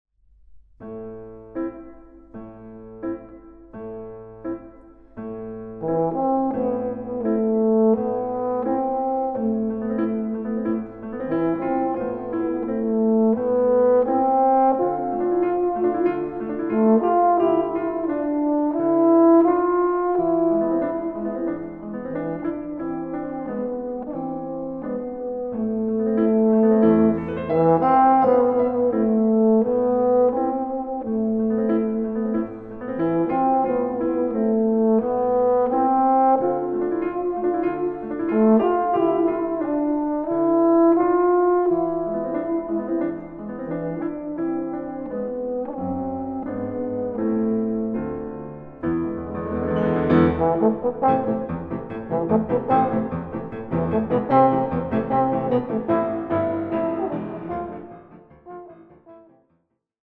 Recording: Mendelssohn-Saal, Gewandhaus Leipzig, 2025
Version for Euphonium and Piano